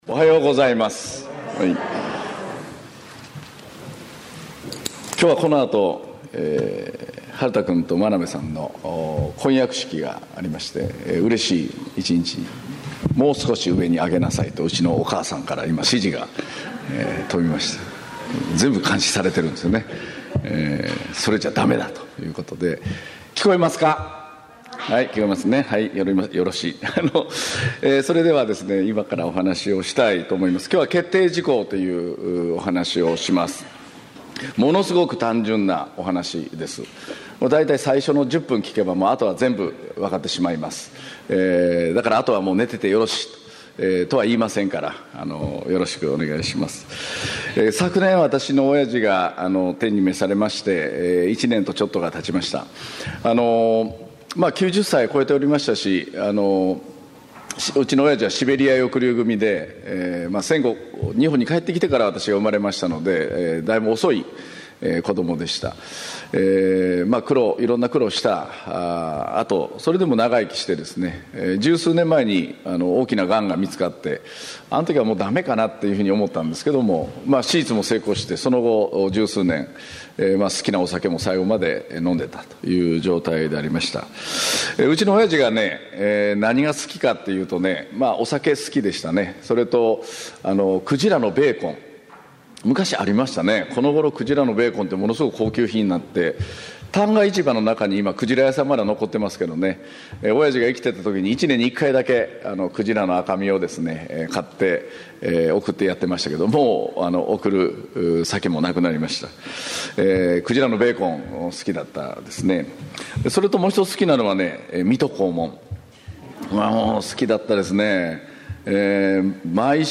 2018年9月9日（日）主日礼拝 宣教題「決定事項―あなたはどこへ向かうのか」 | 東八幡キリスト教会